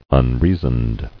[un·rea·soned]